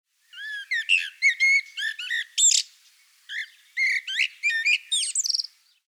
variabel, vorwiegend klangvoll tönend getragen melodisch flötend
0:06 (?/i) 0:26 (?/i) Amsel
Turdus_merula_TSA-short.mp3